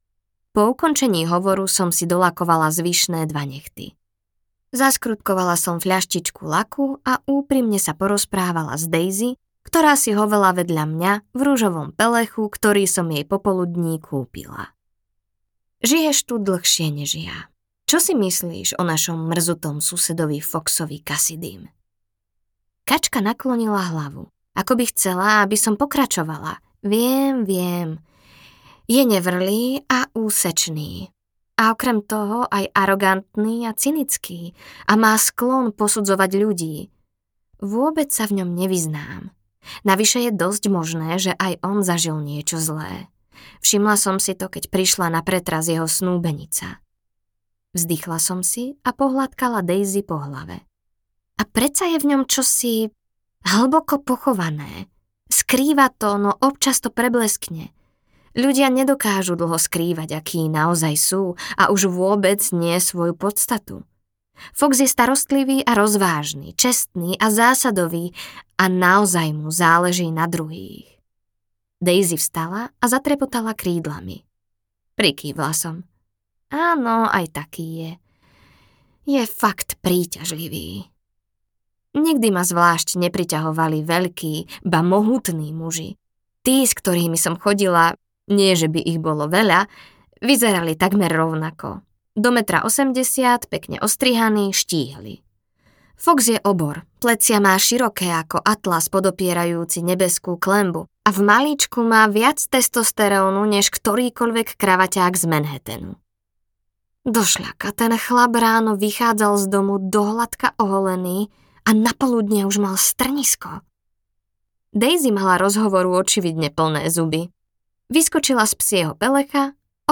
Audio knihaČo sa stane pri jazere
Ukázka z knihy